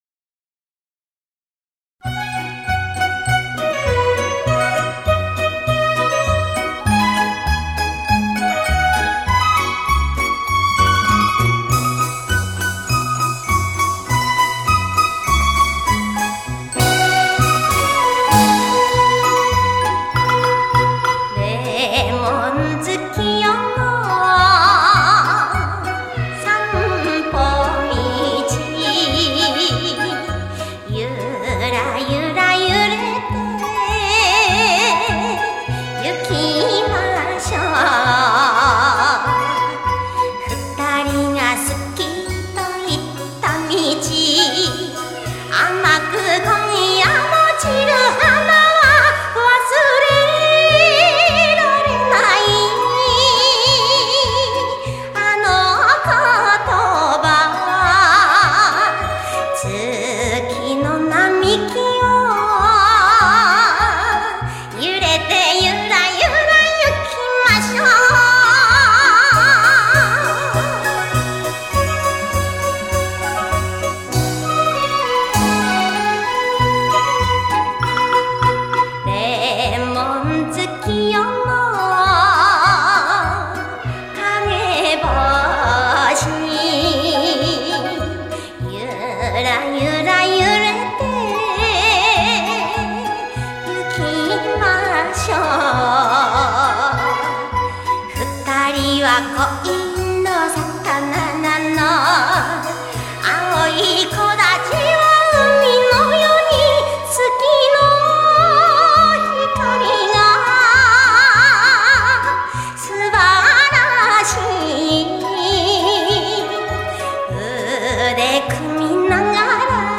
收录日本演歌精选/曲曲动听 朗朗上口